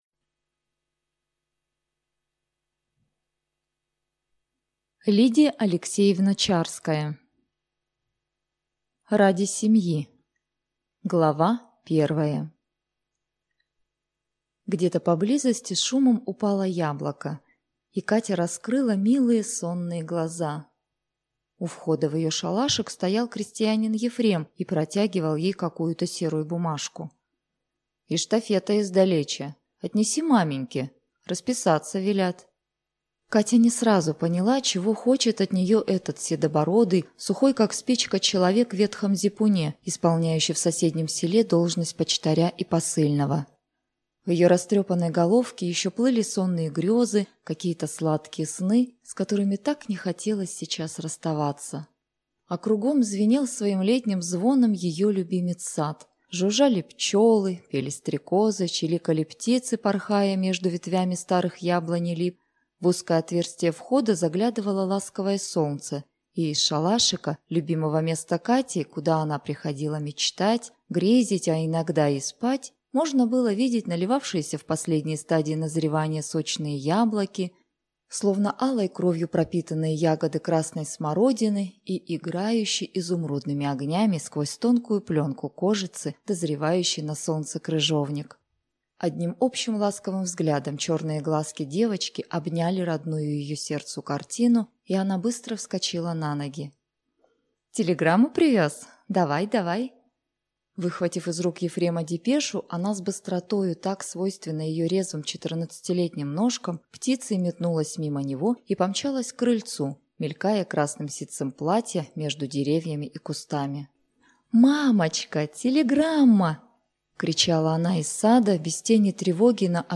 Аудиокнига Ради семьи | Библиотека аудиокниг